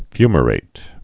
(fymə-rāt)